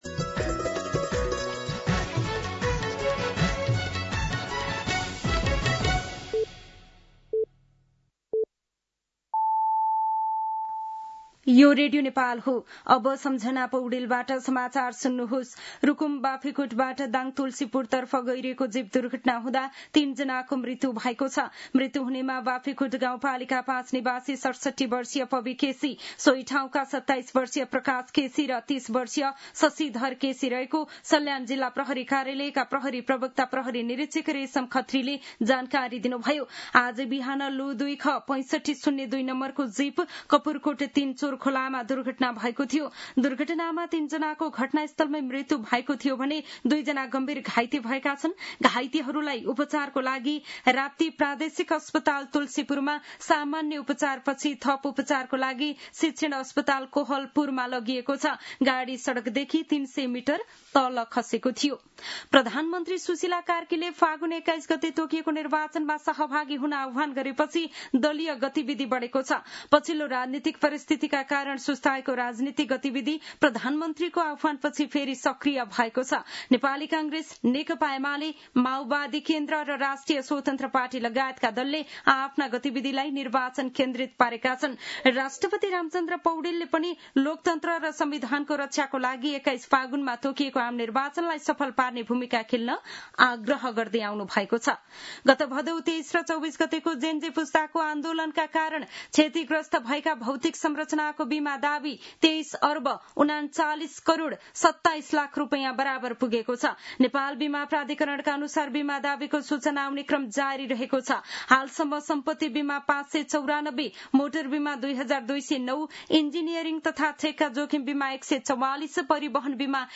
An online outlet of Nepal's national radio broadcaster
दिउँसो १ बजेको नेपाली समाचार : १२ असोज , २०८२